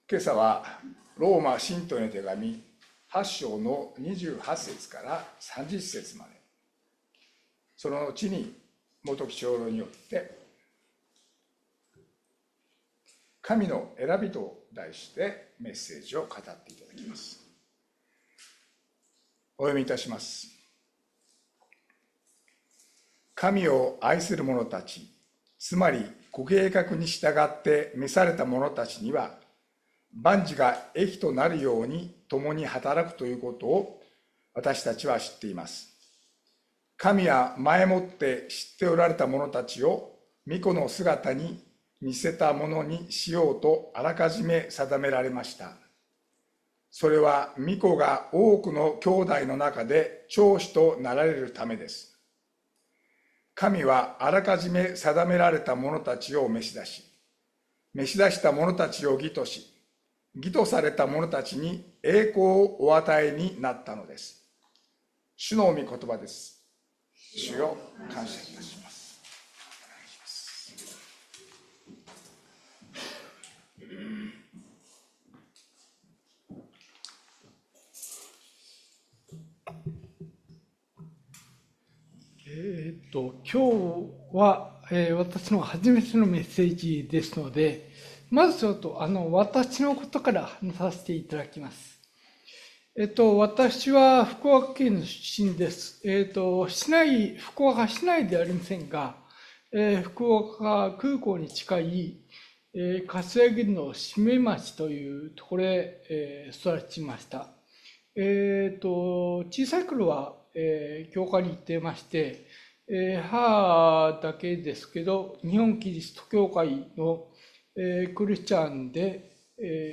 日曜朝の礼拝
礼拝説教を録音した音声ファイルを公開しています。